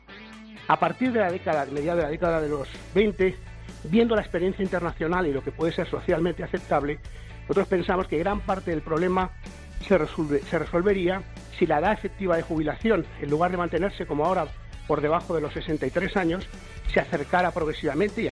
Declaraciones de José Luis Escrivá